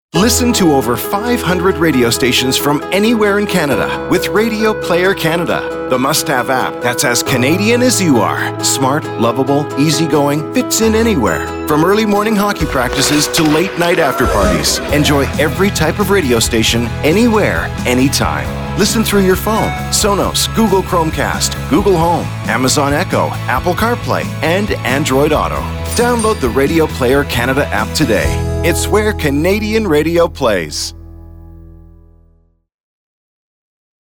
Brand Spots / Drops
The three 30-second audio Brand Spots: